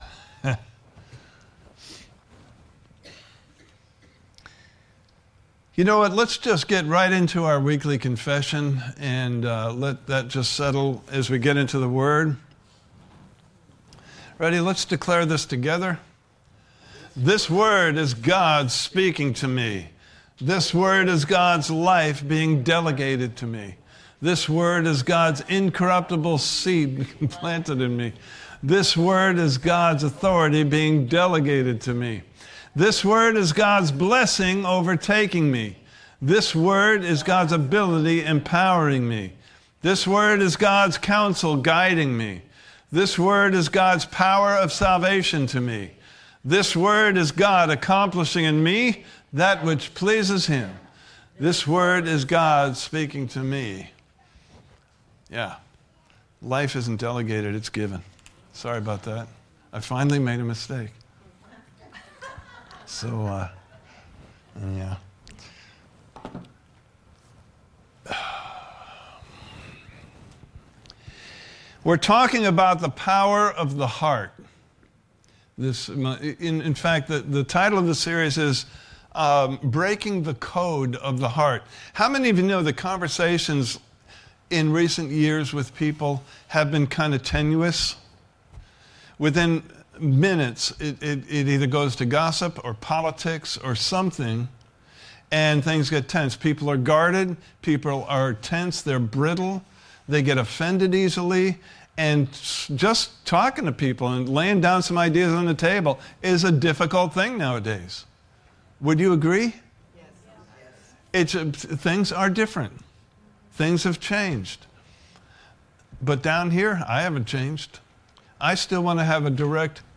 Breaking the Code of the Heart Service Type: Sunday Morning Service « Part 1